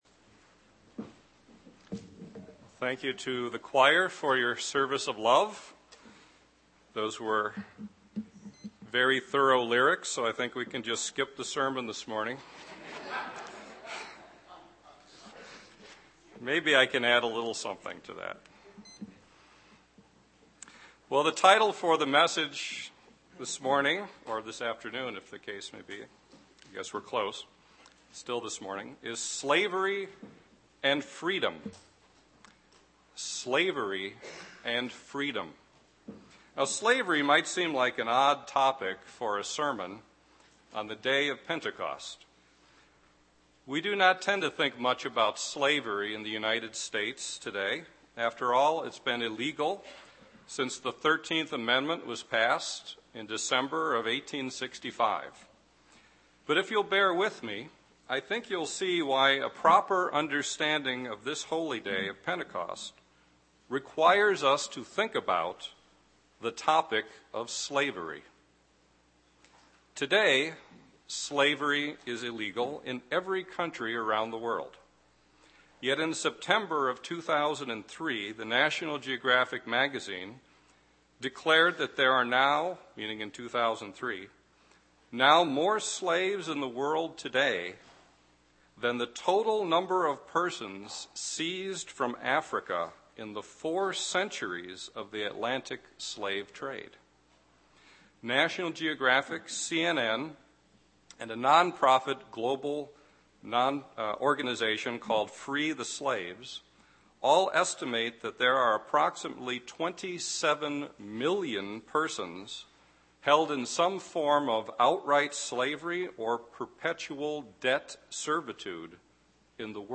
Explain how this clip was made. Why is deliverance from slavery still something God wants us to think about on Pentecost? This message was given on the Feast of Pentecost.